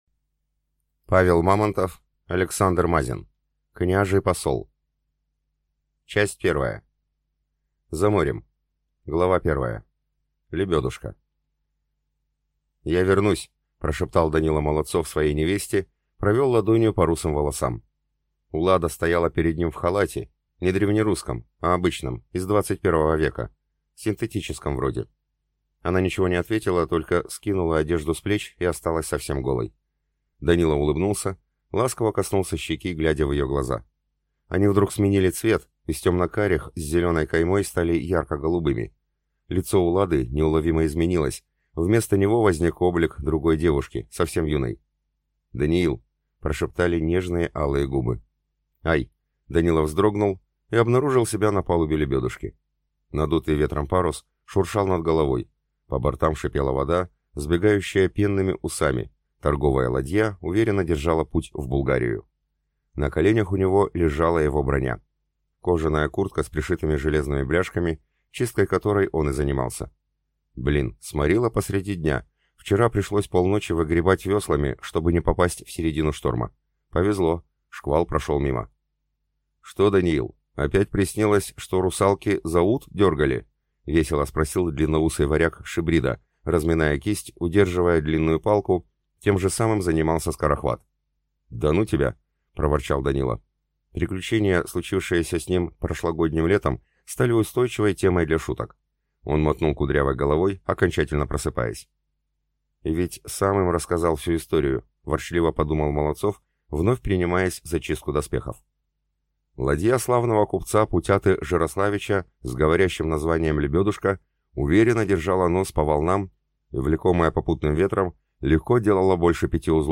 Аудиокнига Княжий посол | Библиотека аудиокниг